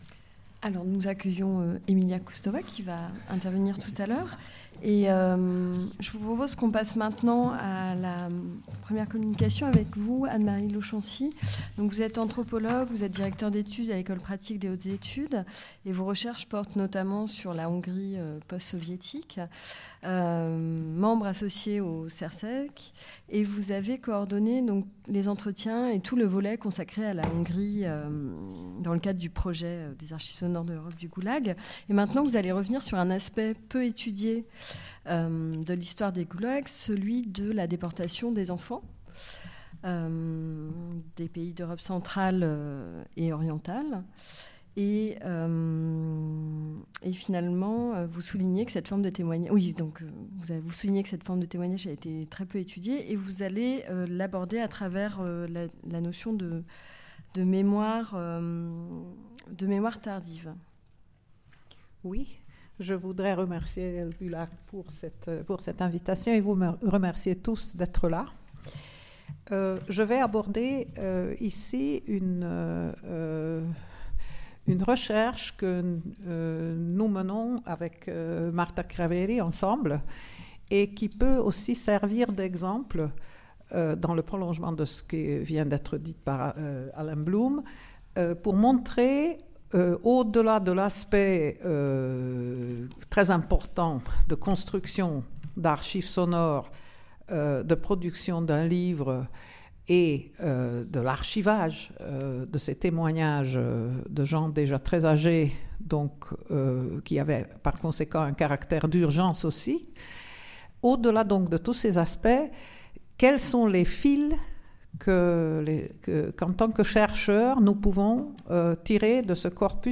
Cette journée d'étude est coordonnée par les missions Action culturelle et bibliothèque numérique de la BULAC et le CERCEC, en partenariat avec RFI et les éditions Autrement, avec le concours de la mission Communication externe de la BULAC.